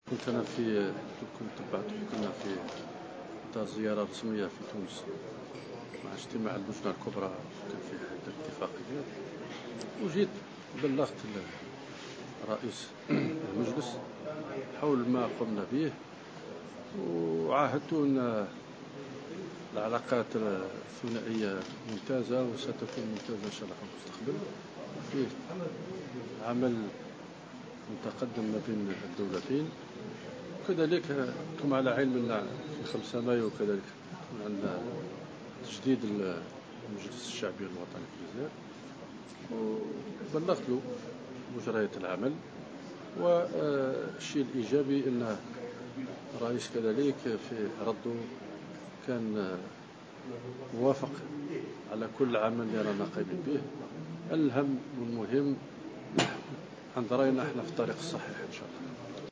قال الوزير الأول الجزائري عبد المالك سلال اثر لقاء جمعه برئيس مجلس نواب الشعب محمد الناصر، إن العلاقات الثنائية بين البلدين مميزة وستكون أحسن خلال الفترة القادمة.